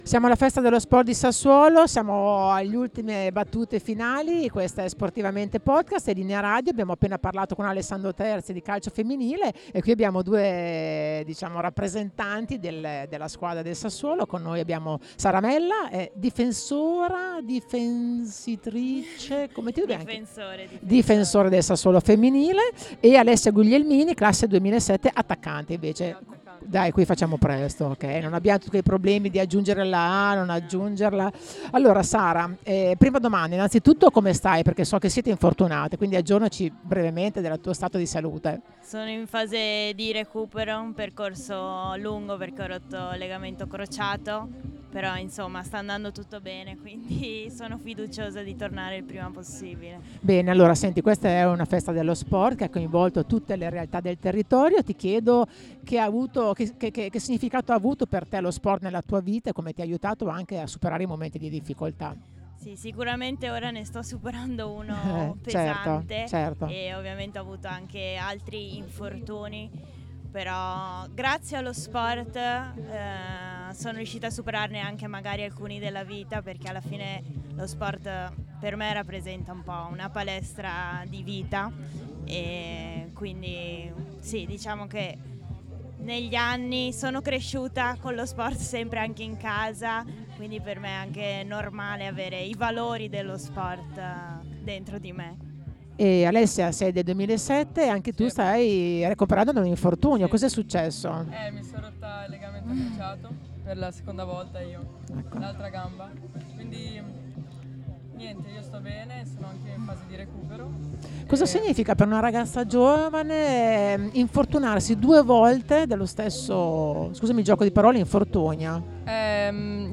Festa dello sport 2025
Intervista